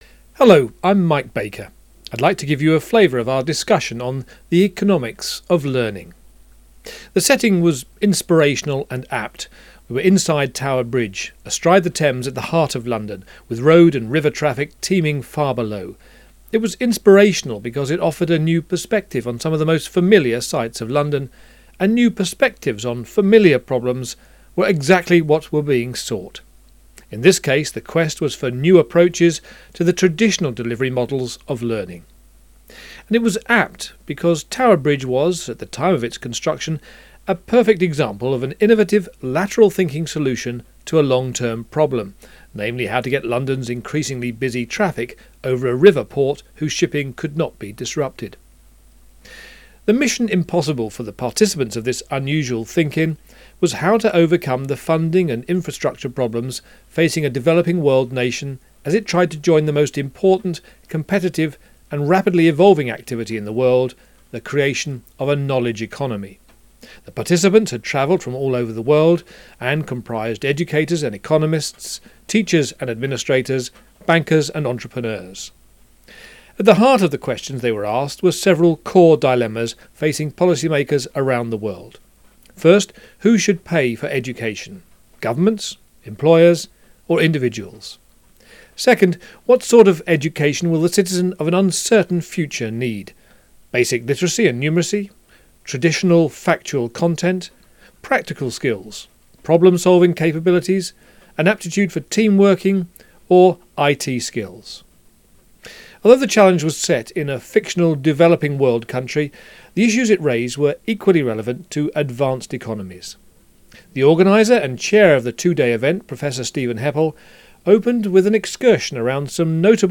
location: tower bridge, london